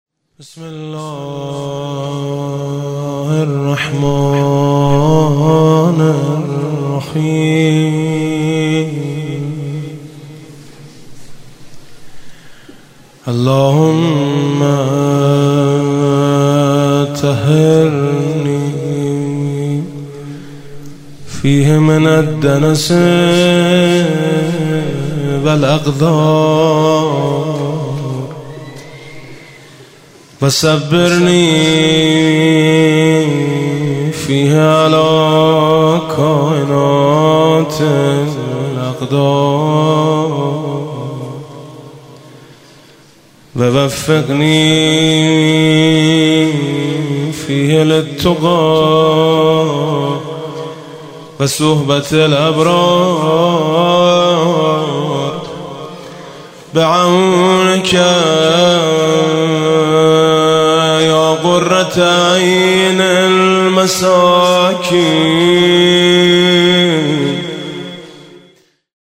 💠 دعای روز سیزدهم ماه مبارک ۱۴۰۰ 🤲 خدایا مرا در این ماه از آلودگی ها و ناپاکی ها پاک کن، و بر شدنی های مورد تقدیرت شکیبایم گردان، و به پرهیزگاری و هم نشینی با نیکان توفیقم ده، به یاری ات ای نور چشم درماندگان 👈 دریافت صوت:
Doa-13Ramazan.mp3